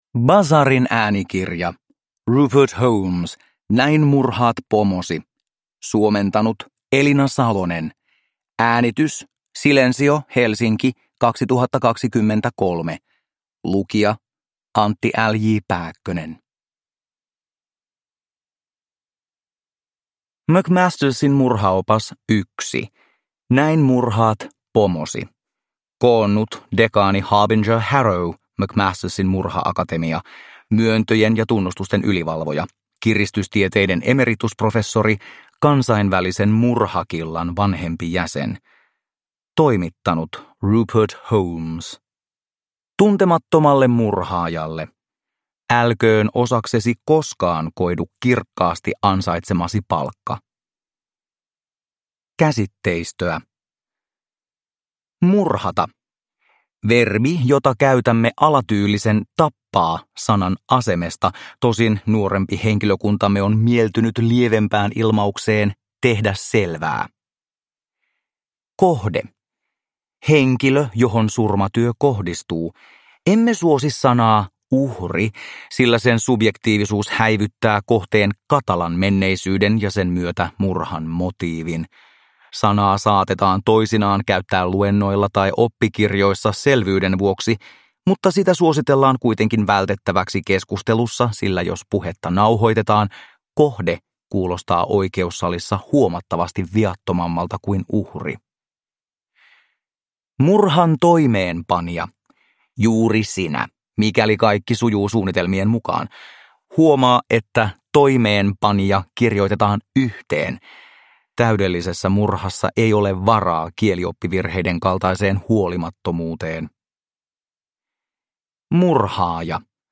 Näin murhaat pomosi – Ljudbok – Laddas ner